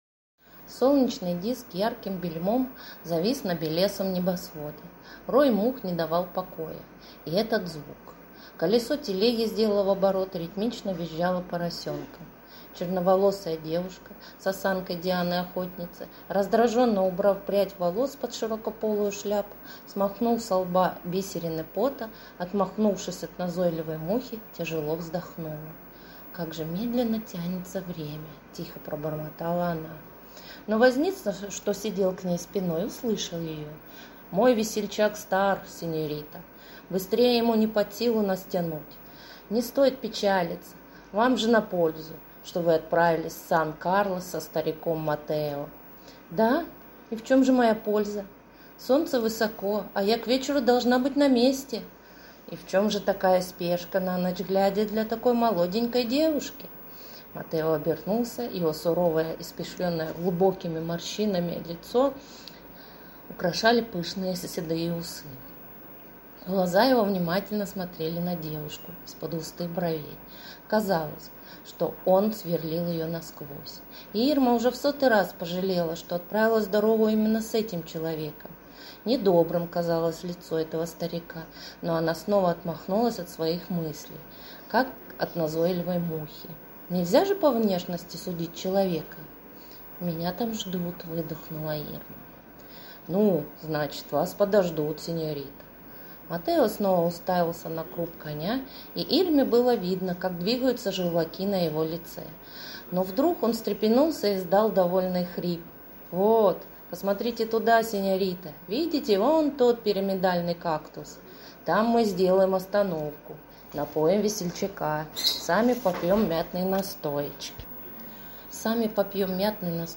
ой как тяжело! у меня язык заплетался... короч я не дочитала... ну вот хоть шото))
У тебя мило получается.